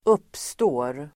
Uttal: [²'up:stå:r]